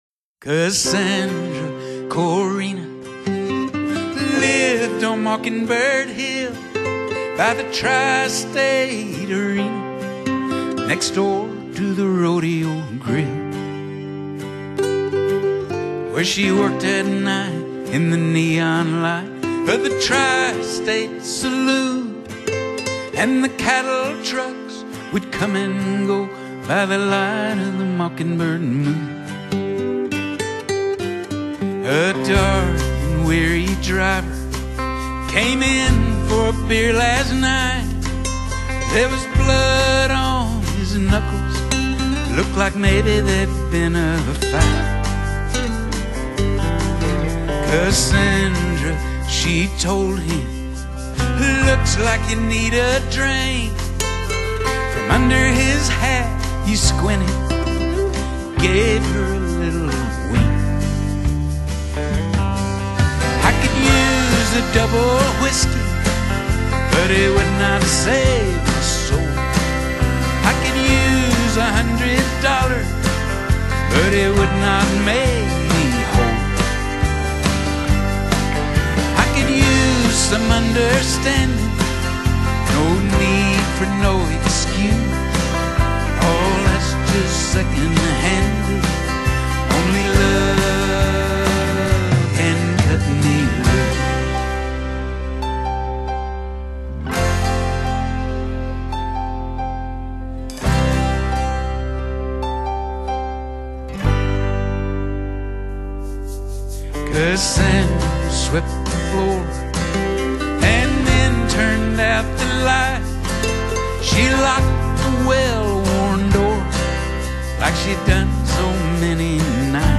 Genre: Country/Outlaw Country